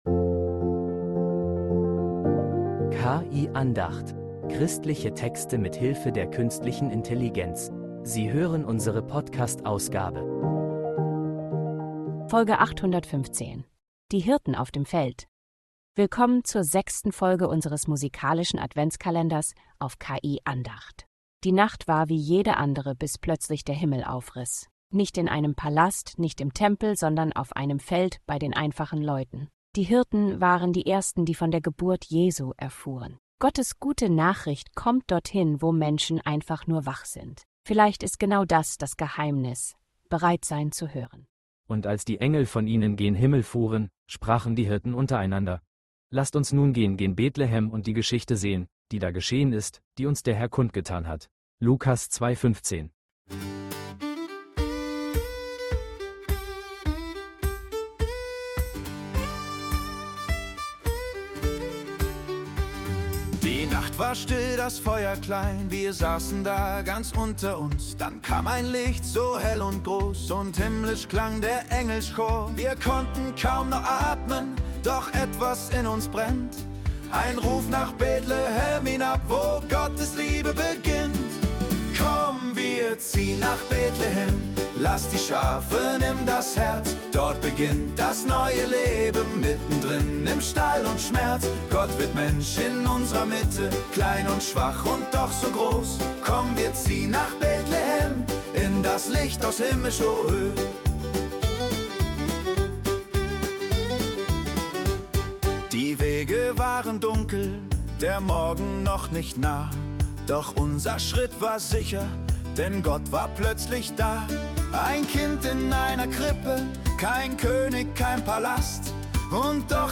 Lebendig, bodenständig, voller Hoffnung....
musikalische Andacht erzählt ihre Geschichte – lebendig,